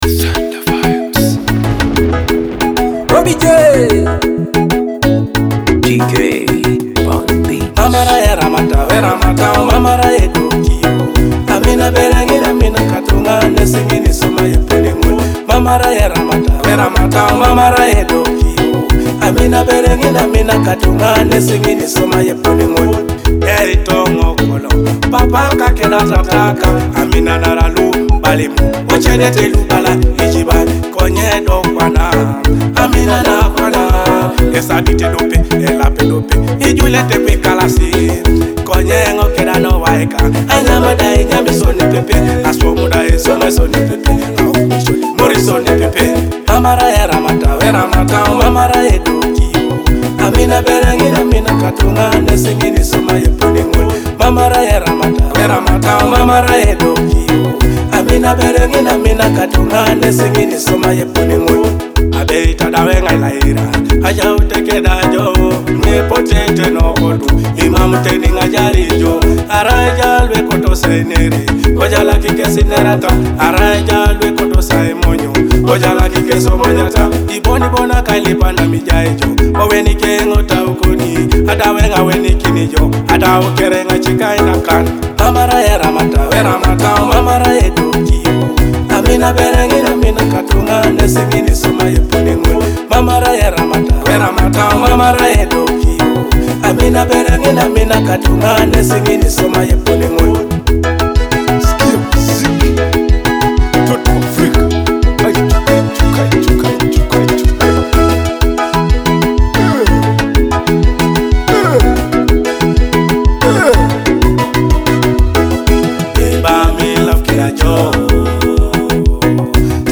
heartfelt fusion of Teso and Afrobeat music